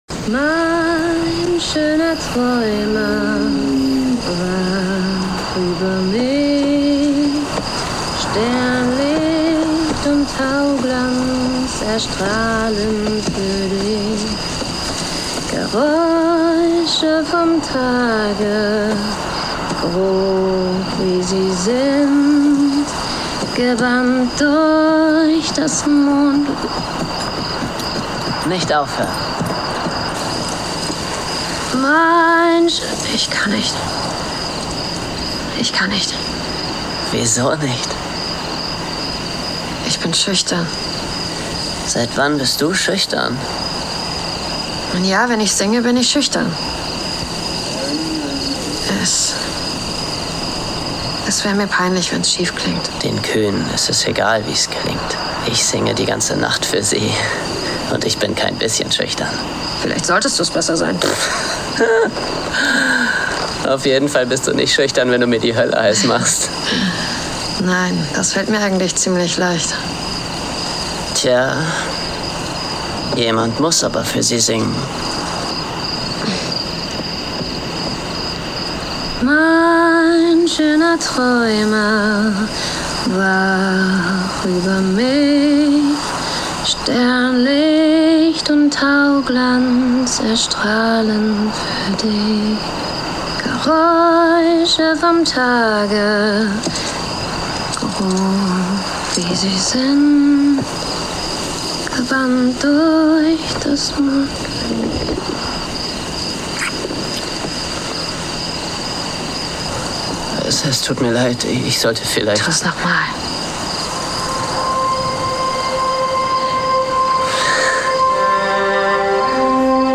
Er ist ein native portugiesischer Voice-over.